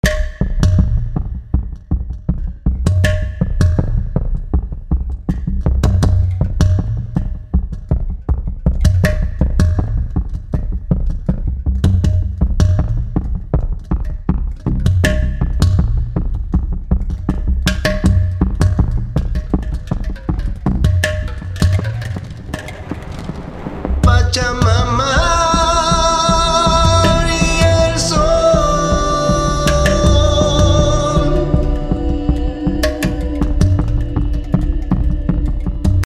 Die indische Percussion könnte auch noch etwas Abwechslung gebrauchen.
Bei Übergängen und Gesangspausen verkürze ich die Echozeit und die Fragmentgröße, wodurch aus den chaotischen Delays Grainwolken entstehen.
Damit die daraus entstehenden Echomuster nicht zu sehr in den Vordergrund geraten und eher nervös als geheimnisvoll wirken, füge ich in den Effektweg in den dritten Slot wiederum Tverb ein, hier mit relativ langer Ausklingphase und etwas reduziertem Anteil des Frontmikros.
Das Intro hört sich nun folgendermaßen an: